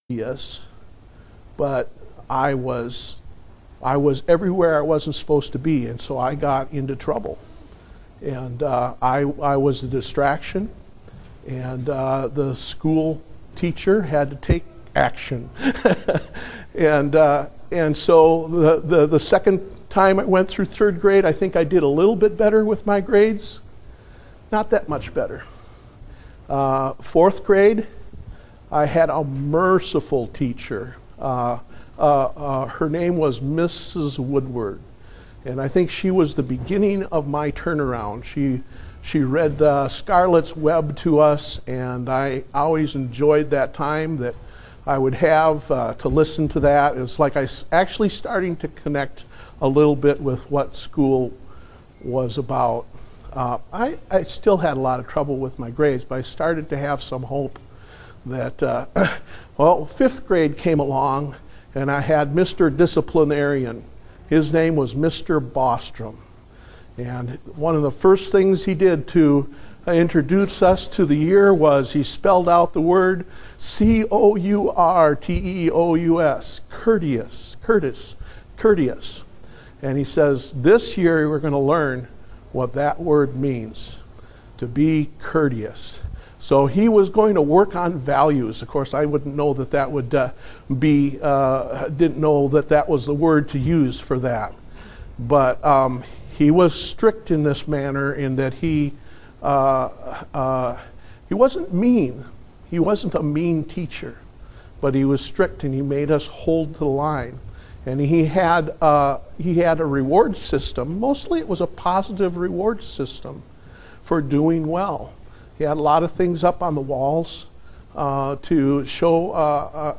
Date: May 29, 2016 (Adult Sunday School)